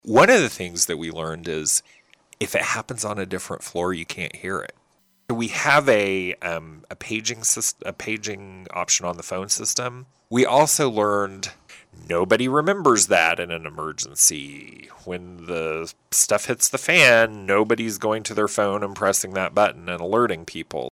Saline County Administrator Phillip Smith-Hanes joined in on the KSAL Morning News Extra and recounted the drill that took place in two phases. Smith-Hanes says the run-through provided a real time look at making quick decisions in a high stress situation.